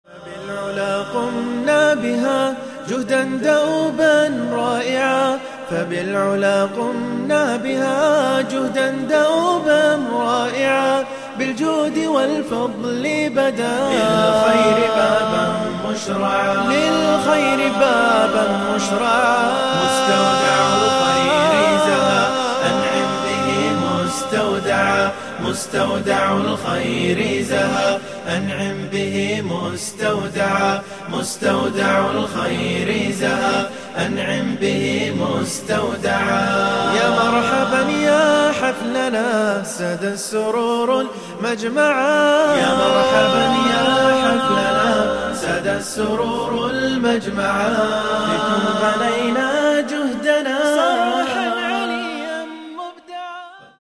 من هو هذا المنشد :